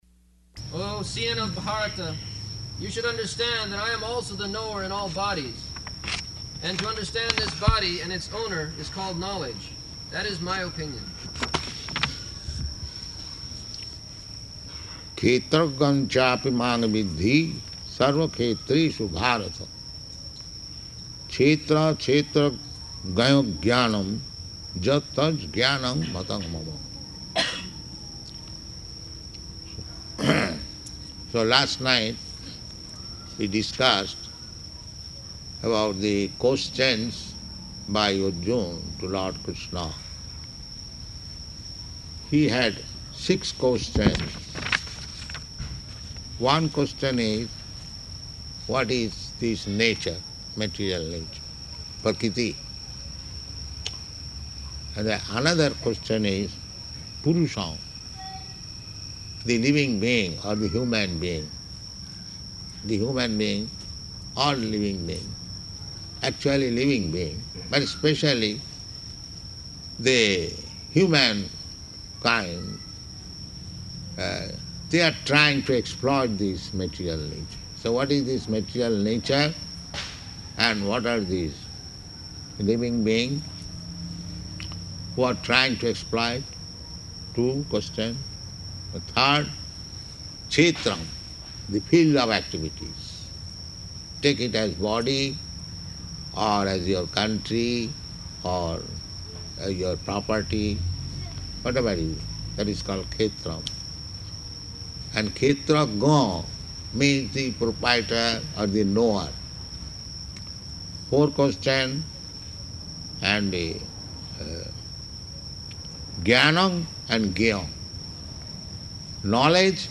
Location: Miami